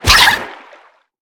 Sfx_creature_featherfish_flinch_01.ogg